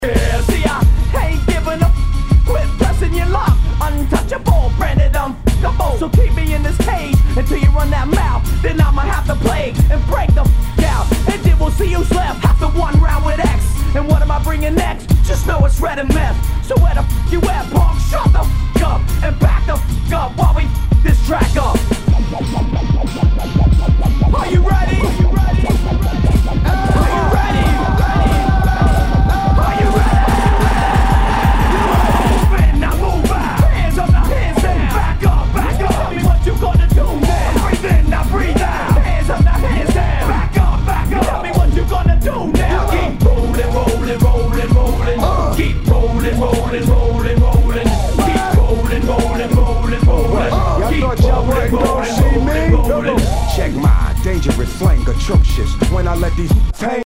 HIPHOP/R&B
[VG ] 平均的中古盤。スレ、キズ少々あり（ストレスに感じない程度のノイズが入ることも有り）